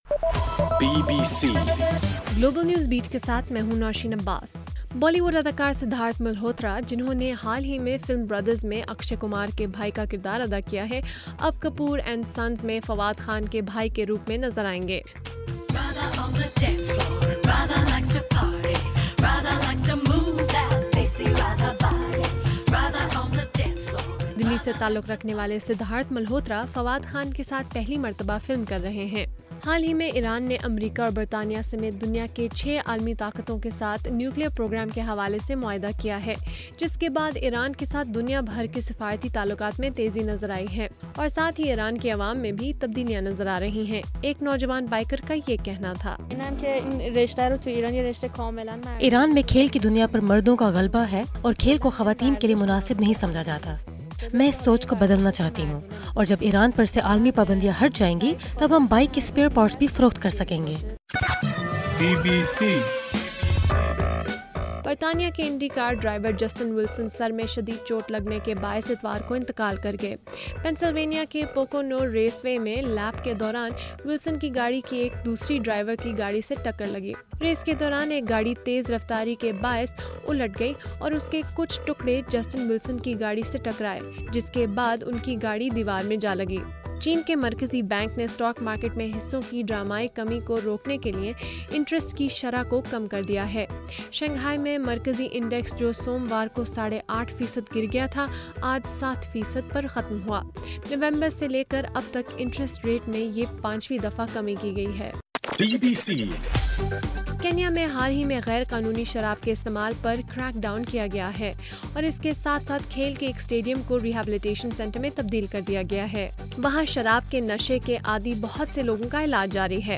اگست 25: رات 12 بجے کا گلوبل نیوز بیٹ بُلیٹن